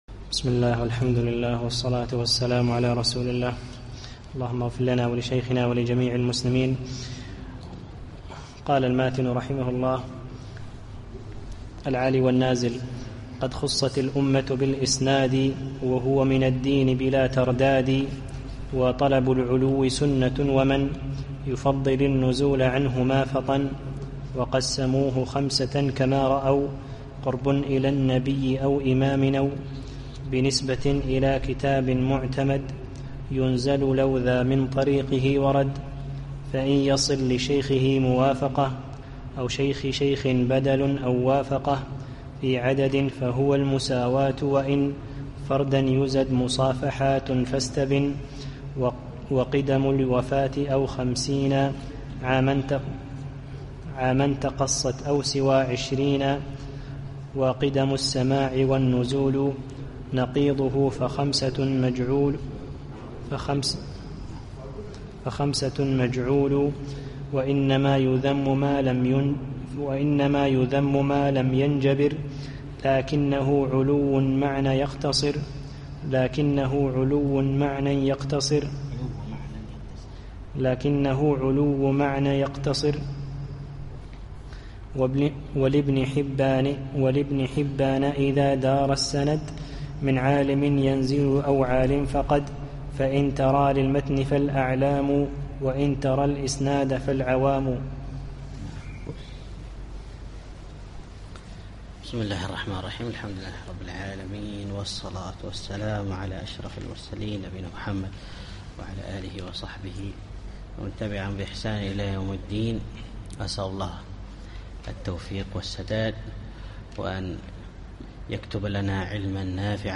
الدرس السابع والعشرون